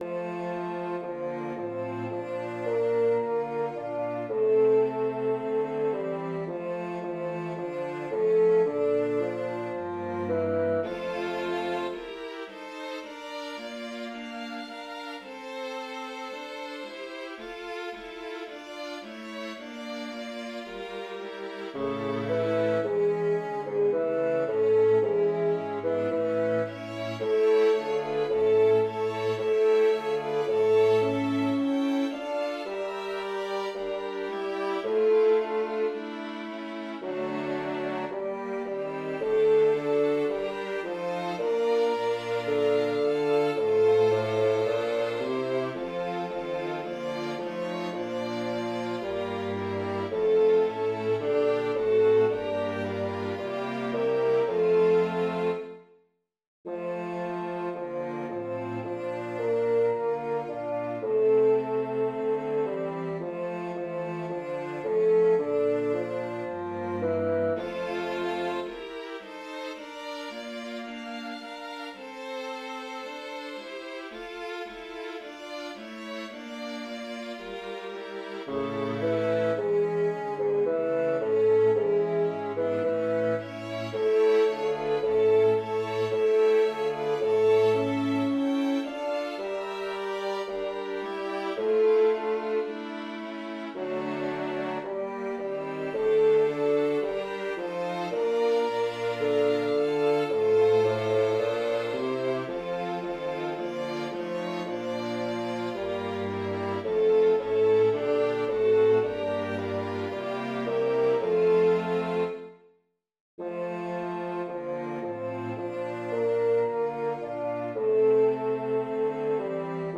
Erst ein Ton tiefer, dann Wiederholung original